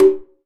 9HICONGOP.wav